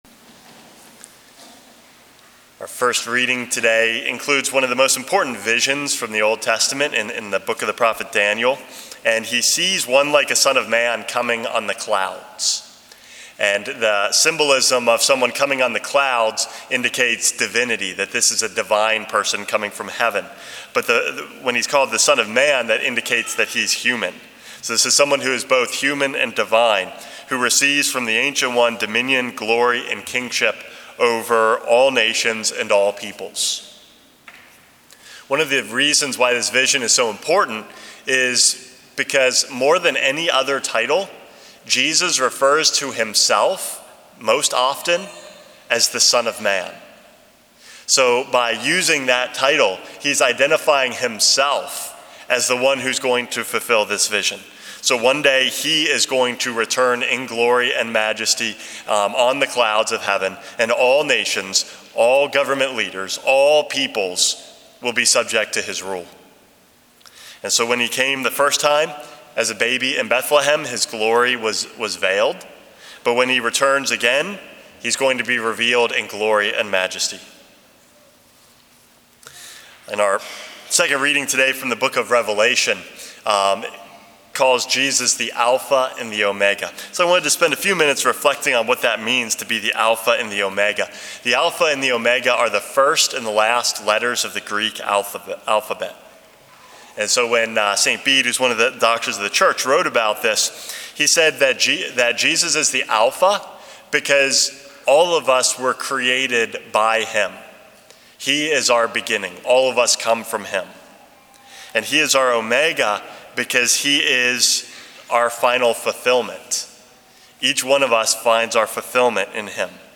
Homily #425 - The Alpha and Omega